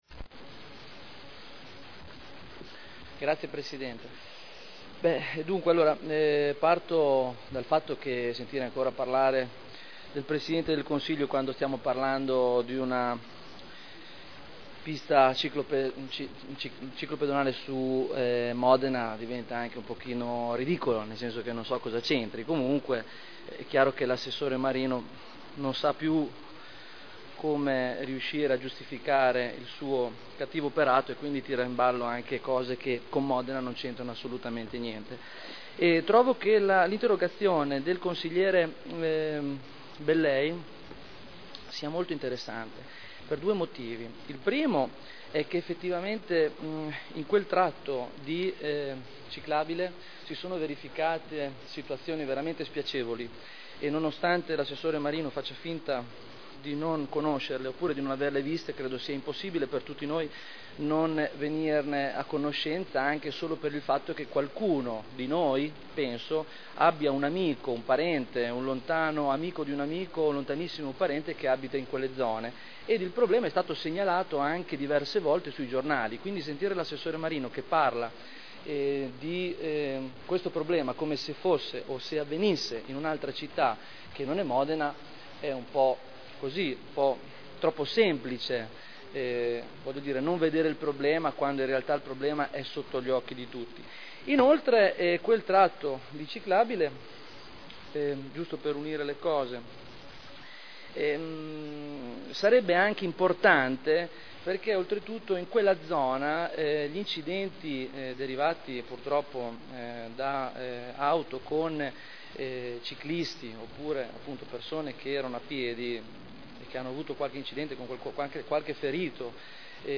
Stefano Barberini — Sito Audio Consiglio Comunale